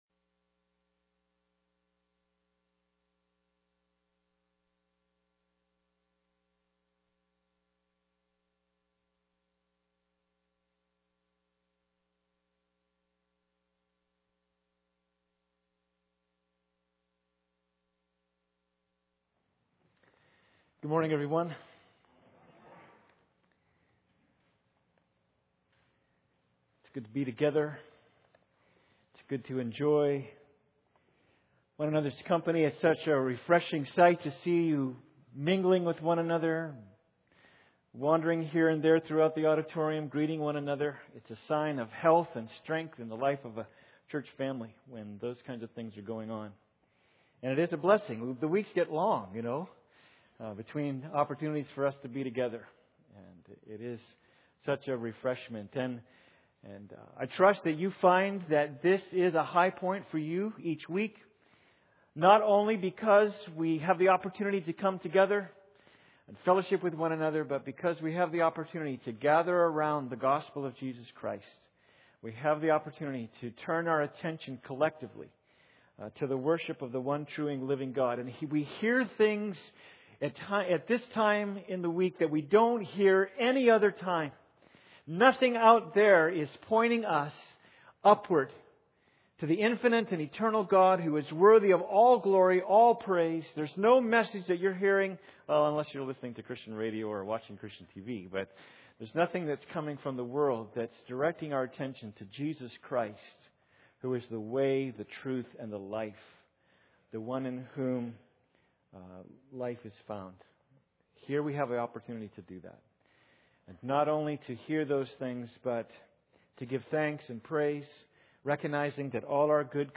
Passage: Philippians 1:9-11 Service Type: Sunday Service